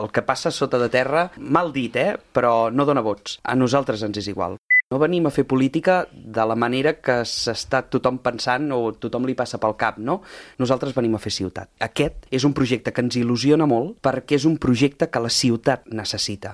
Ho ha dit el portaveu de Junts a l’Ajuntament de Calella i segon tinent d’Alcaldia de Fires, Activitat cultural i Serveis municipals, Josep Grima, a l’entrevista política de RCT.